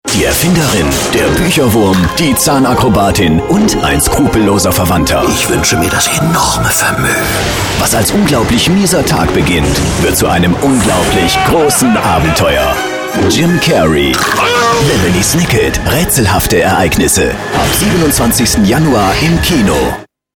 Werbesprecher, Sprecher für PC-Spiele
Sprechprobe: eLearning (Muttersprache):
german voice over artist.